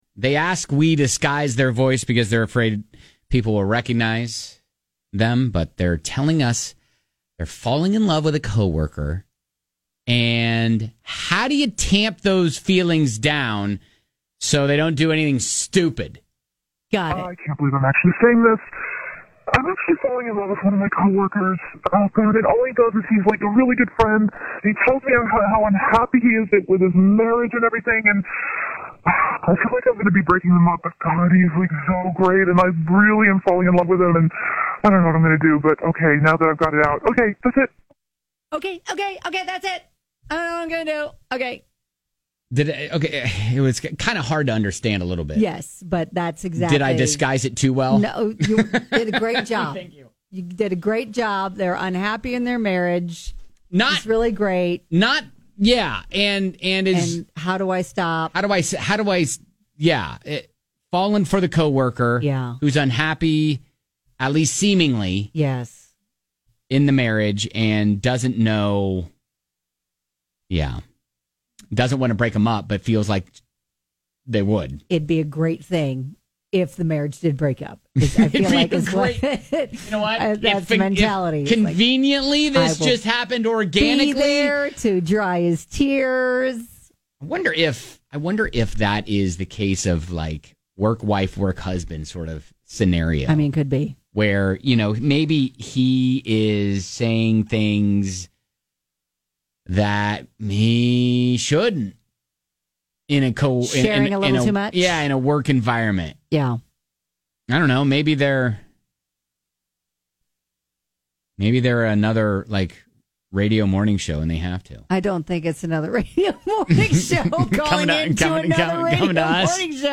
We chatted with this listener after the show and they had an age old question…. how do you stop falling in love with your co-worker?